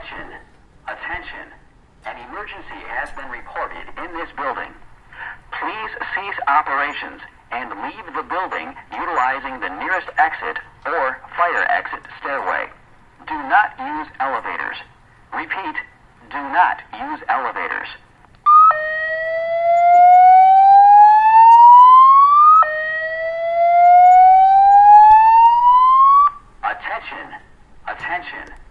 Sounds of evacuation_ Voice of announcer who says in English to leave the building, the fire alarm has gone off
• Category: Fire alarm
• Quality: High